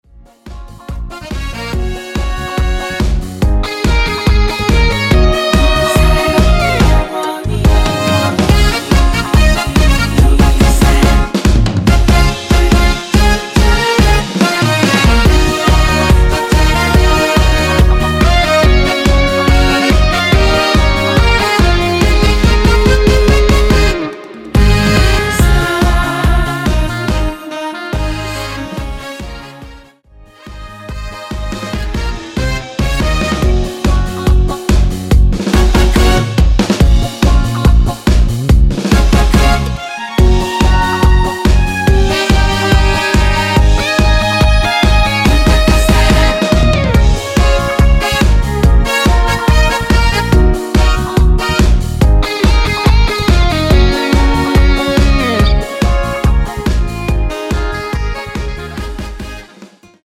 원키에서(-2)내린 코러스 포함된 MR입니다.
Db
앞부분30초, 뒷부분30초씩 편집해서 올려 드리고 있습니다.